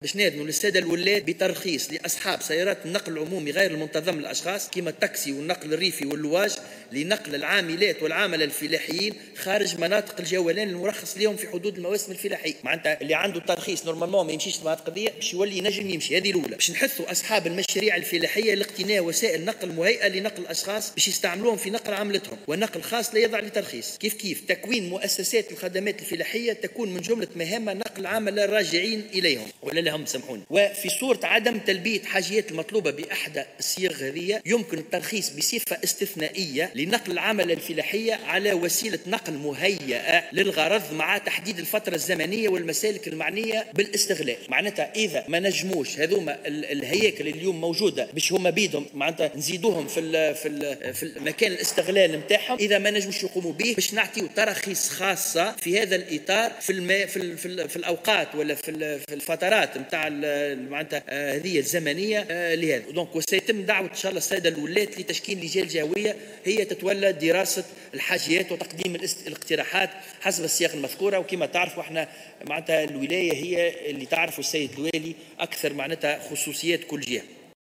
وأضاف الوزير اليوم خلال جلسة عامة عقدها مجلس نواب الشعب للمصادقة على مشروع ميزانية وزارة النقل لسنة 2019، أنه سيتم أيضا حث أصحاب المشاريع الفلاحية على اقتناء وسائل نقل مهيّأة لنقل العملة، إضافة إلى تكوين مؤسسات للخدمات الفلاحية من بين مهامها نقل العاملين الراجعين بالنظر إليها.